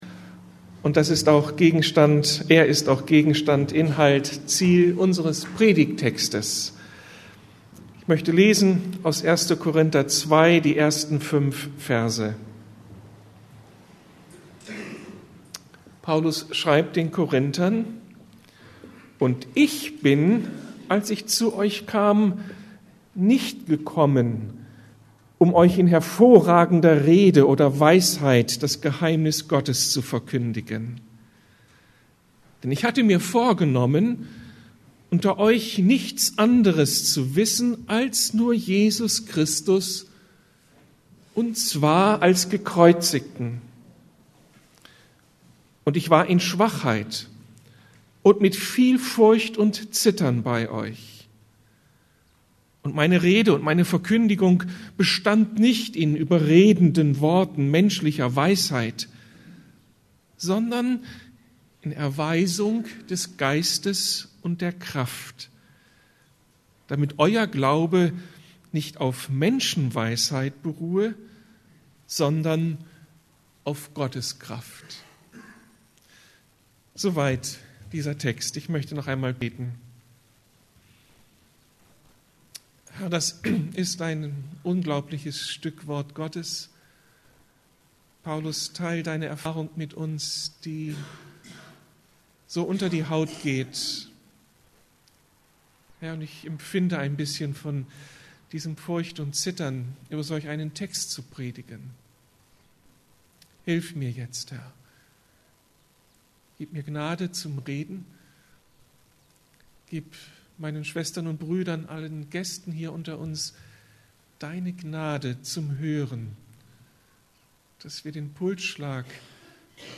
Der gekreuzigte Christus gehört ins Zentrum ~ Predigten der LUKAS GEMEINDE Podcast